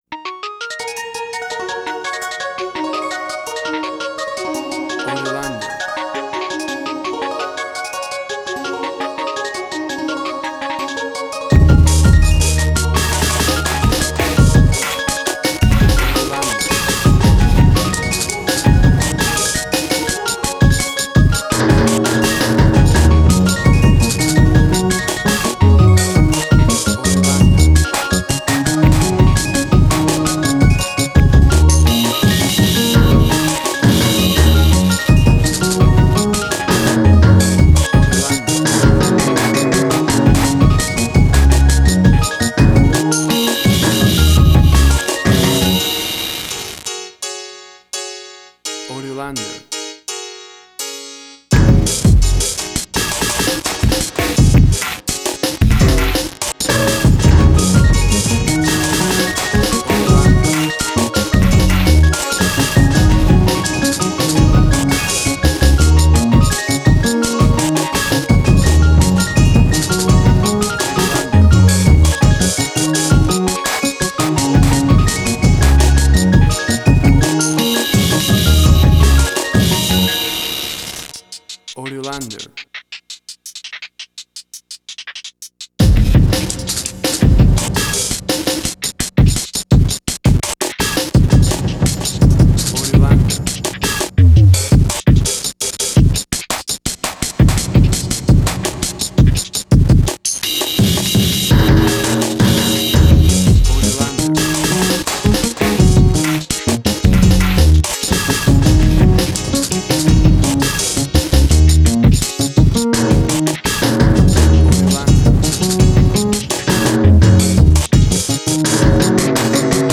IDM, Glitch.
Tempo (BPM): 168